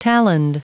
Prononciation du mot taloned en anglais (fichier audio)
Prononciation du mot : taloned